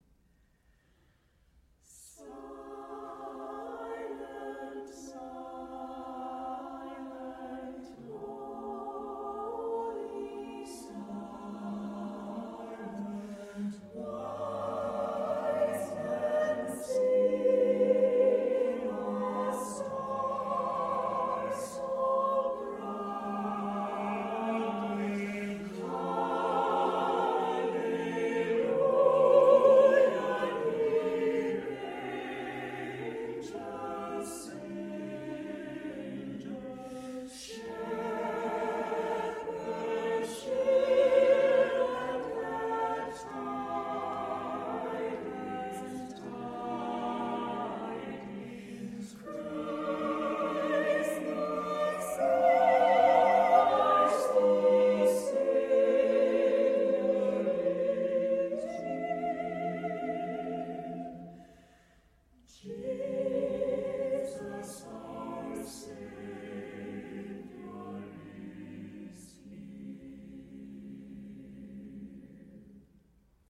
Holidays a cappella - Chicago a cappella